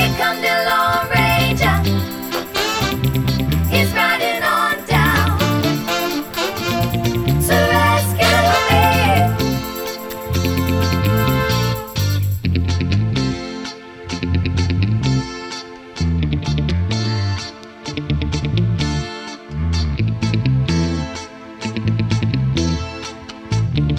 Minus Drums Pop (1970s) 3:51 Buy £1.50